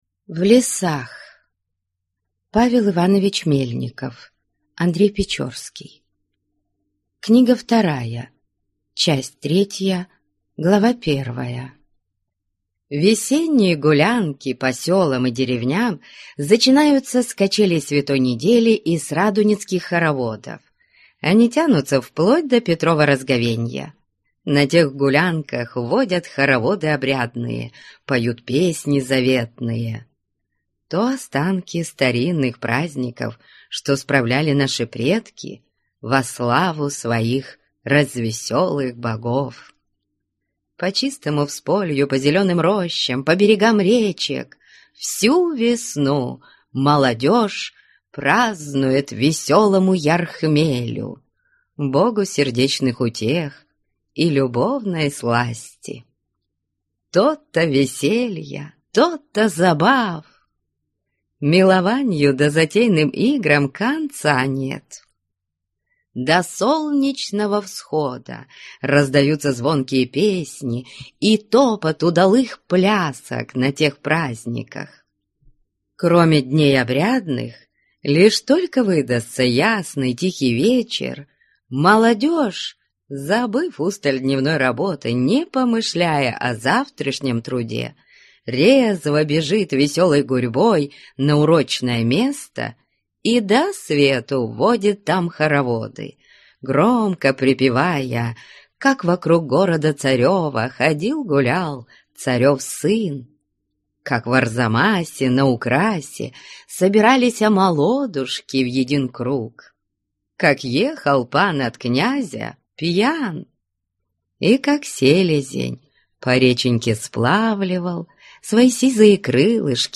Аудиокнига В лесах (часть третья) | Библиотека аудиокниг